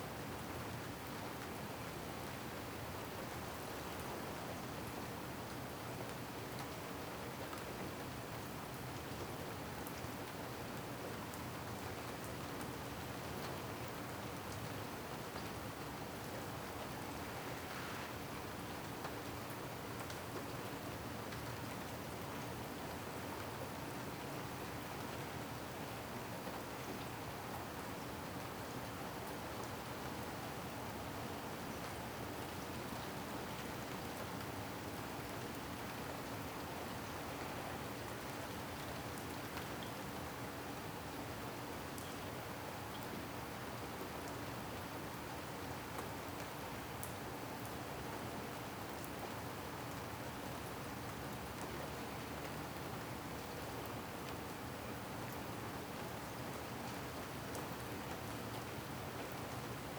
Weather Afternoon Light Rain ST450 02_ambiX.wav